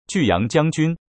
巨陽（ジューヤン）将軍
*3 音声は音読さんを利用